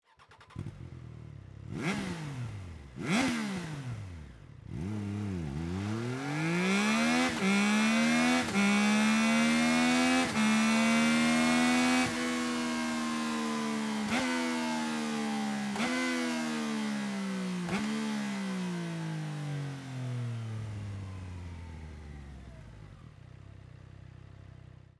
Sound Serienauspuff